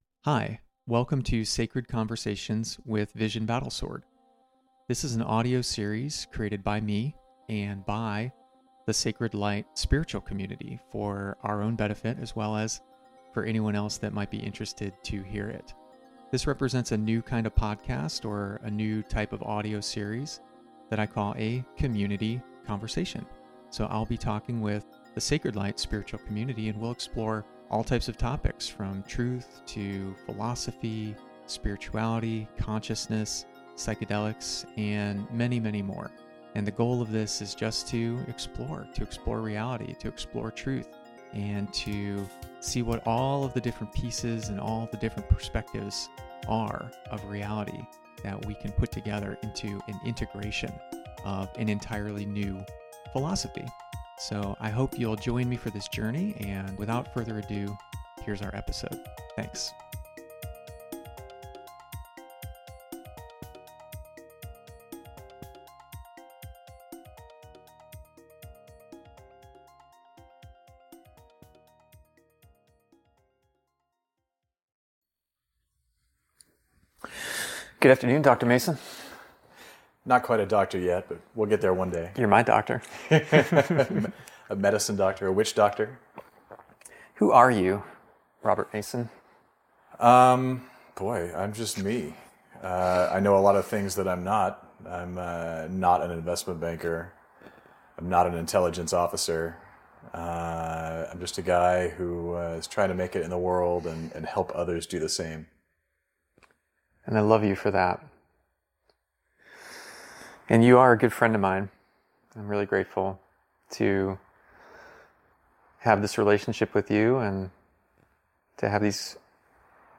From personal belief systems to universal standards, and the delicate dance between faith and truth, they explore how trust shapes our reality. Discover the secrets of effective apologies, the power of empathy, and the courage of self-trust. Tune in to this profound conversation and transform how you understand trust—both within yourself and in the world around you.
conversation02-trust.mp3